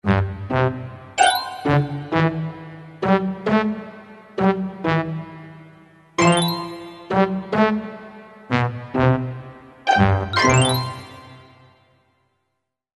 Звук слона из хобота